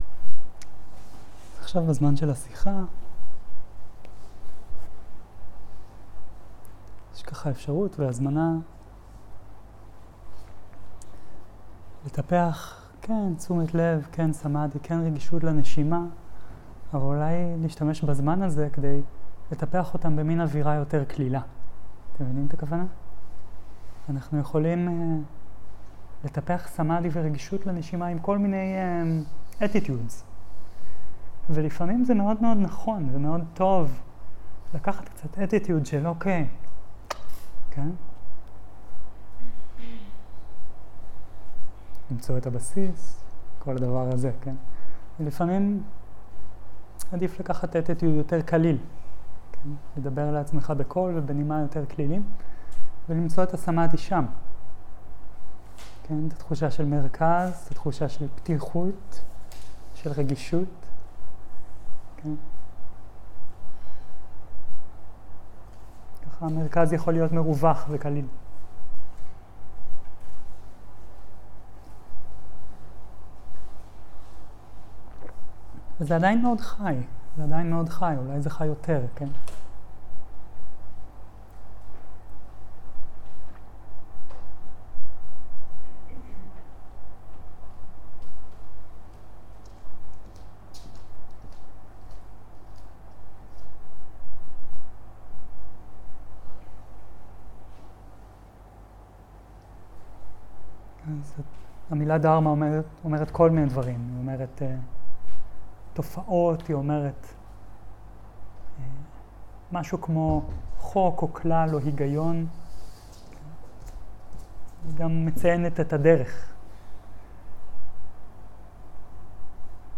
שיחת דהרמה - אברי ההתעוררות